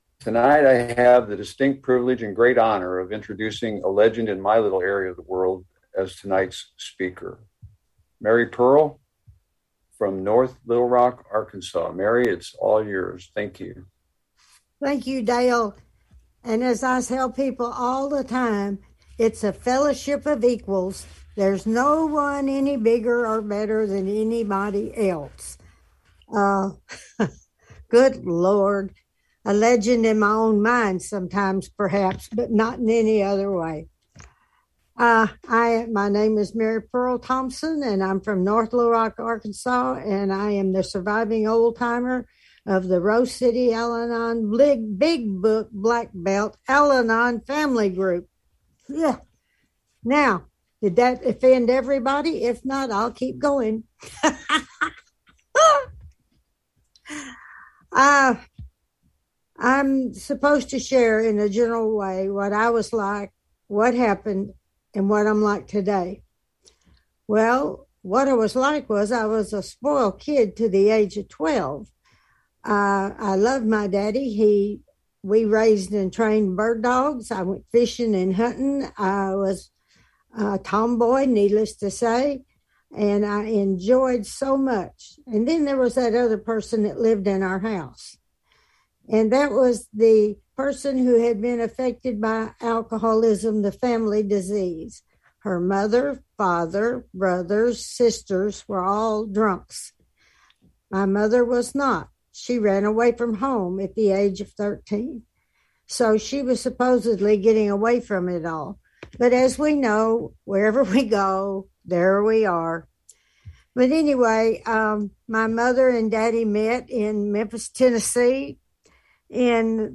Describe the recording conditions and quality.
Georgia State Al-Anon/Alateen Convention - 2021 - Virtual